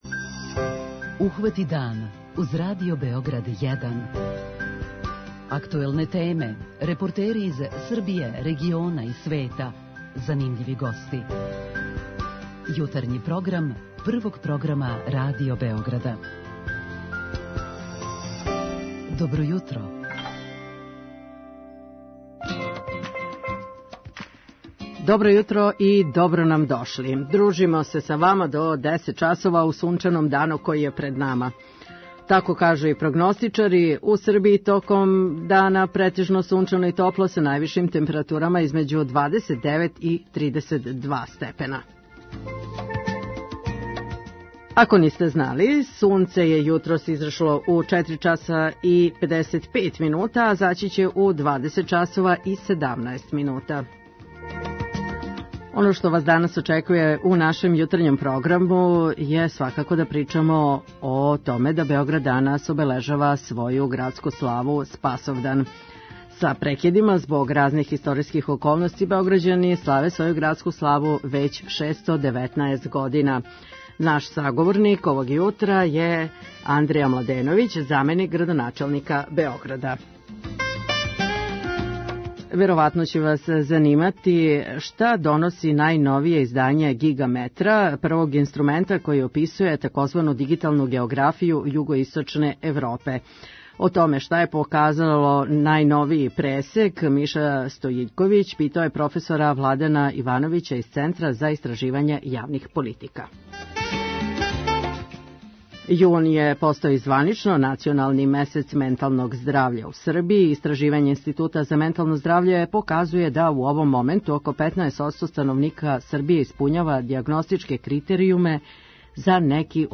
Наш саговорник овог јутра је Андреја Младеновић, заменик градоначелника Београда.